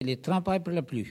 Localisation Saint-Urbain
Catégorie Locution